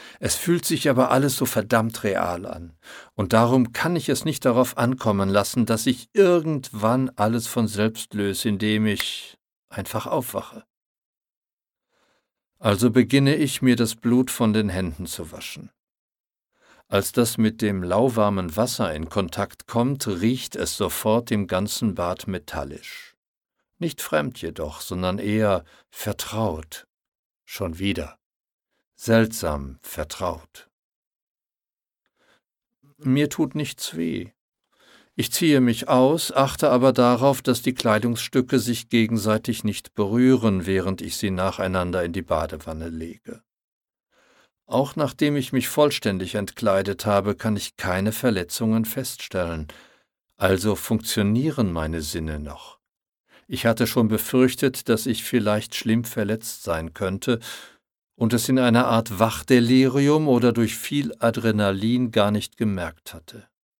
Die Hörgeschichte gibt’s als Download beim Hörmordkartell.